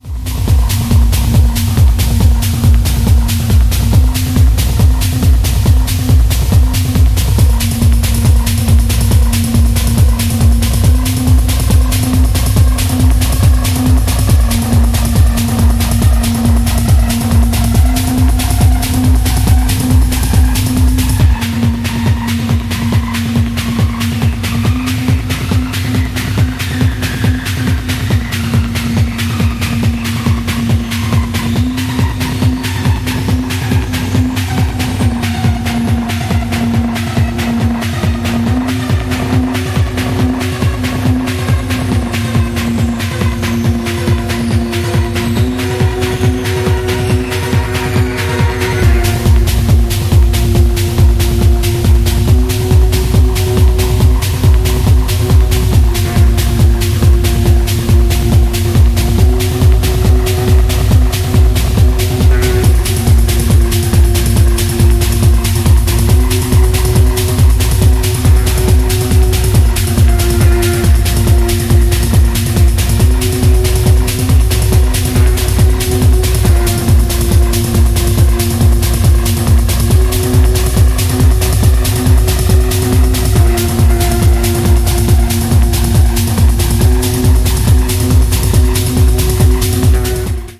four hard-hitting techno cuts